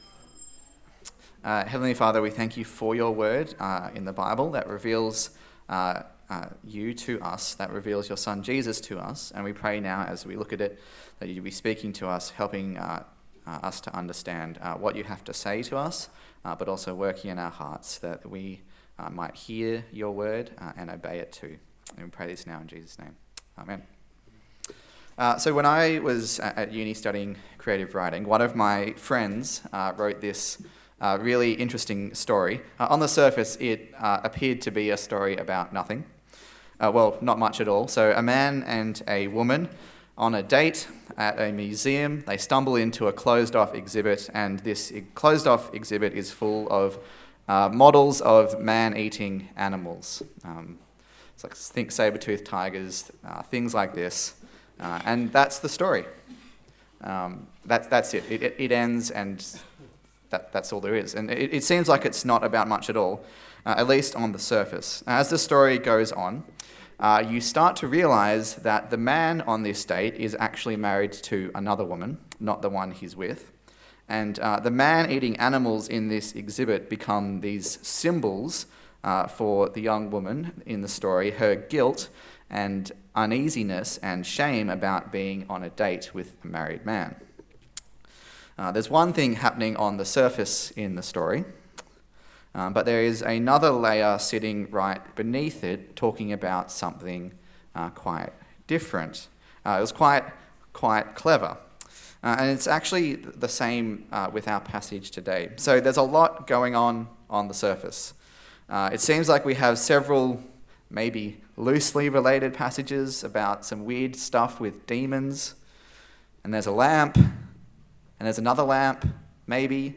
A sermon in the series on the Gospel of Luke
Luke Passage: Luke 11:14-36 Service Type: Sunday Service